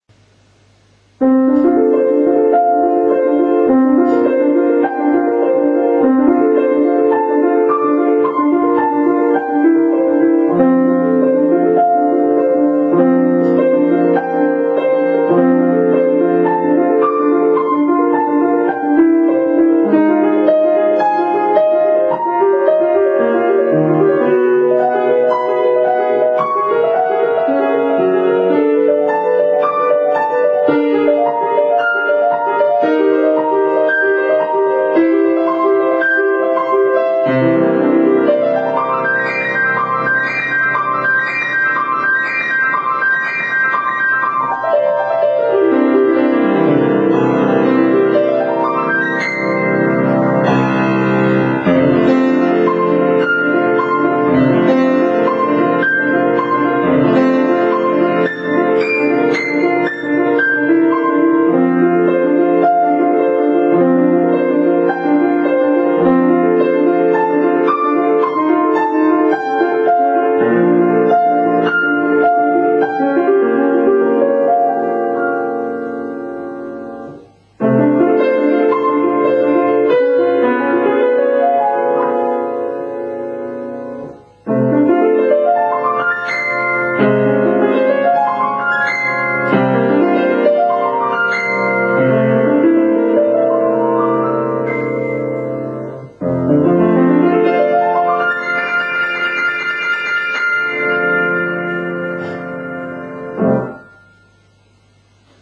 とてもロマンティックな雰囲気の音楽なので、大人の方でも十分目標となり、また弾いていて楽しめる曲だと思います。
ペダルは多く使いますが、ペダルのタイミングがわりと単純なので、ペダルの練習としてもぴったりかもしれません。